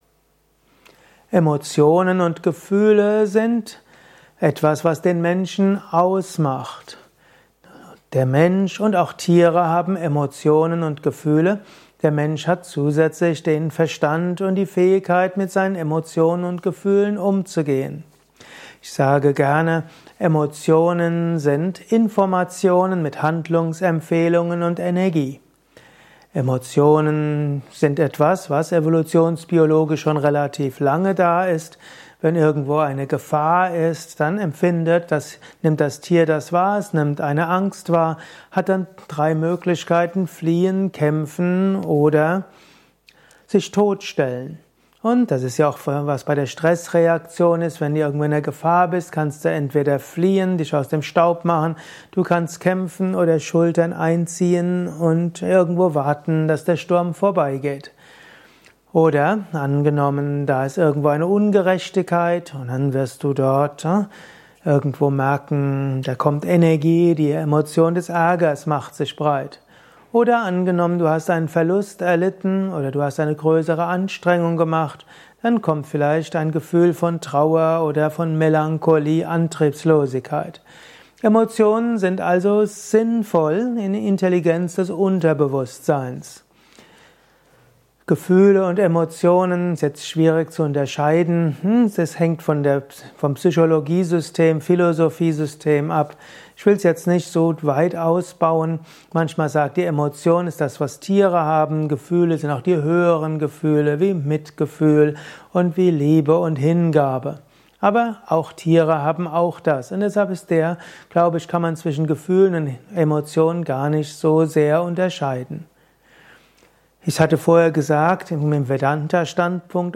Dieser Vortrag ist Teil des Liebe Podcasts